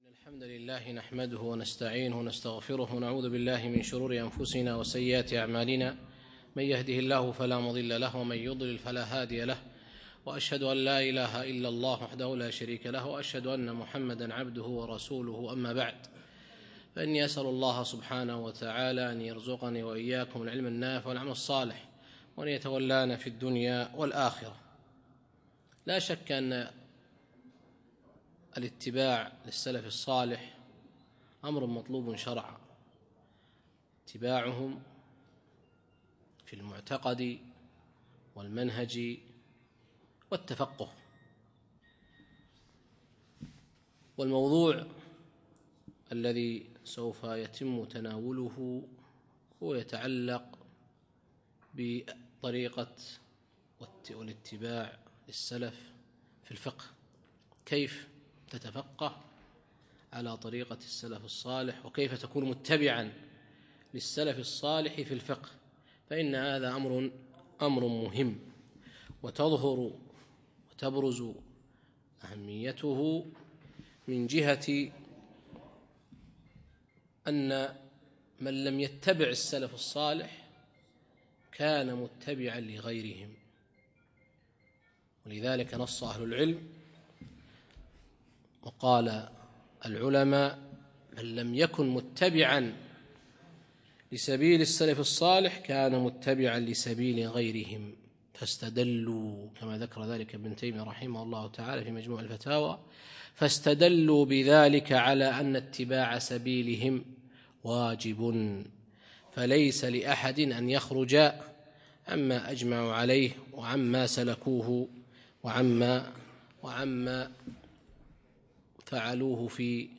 يوم الخميس 10 جمادى ثاني 1438 الموافق 9 3 2017 في مسجد مضحي الكليب العارضية